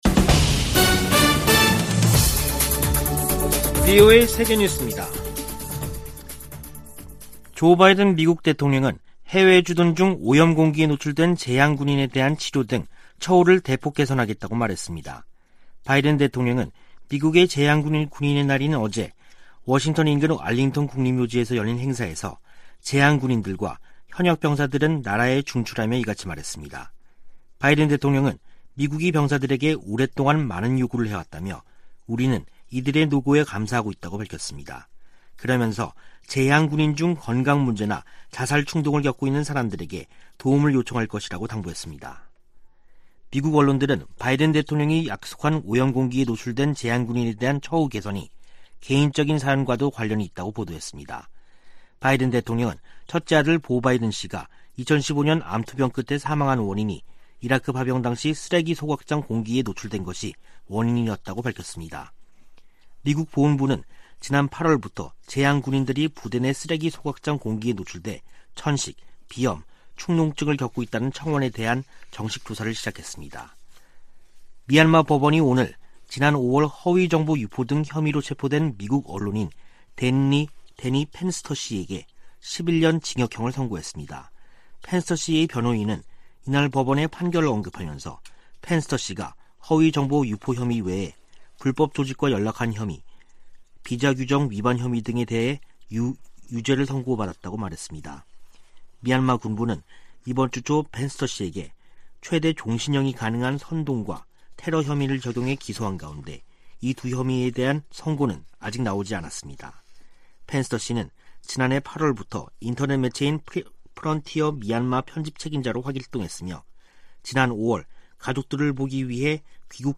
VOA 한국어 간판 뉴스 프로그램 '뉴스 투데이', 2021년 11월 12일 3부 방송입니다. 미국과 종전선언의 큰 원칙에 합의했다는 한국 외교부 장관의 발언이 나오면서 실제 성사 여부에 관심이 쏠리고 있습니다. 북한이 핵실험을 유예하고 있지만 미사일 탐지 회피 역량 개발에 집중하고 있다고 미국 유력 신문이 보도했습니다. 미국 정부가 캄보디아와 연관된 미국 기업들에 대한 주의보를 발령하면서, 북한의 현지 불법 활동에 대해서도 주의를 당부했습니다.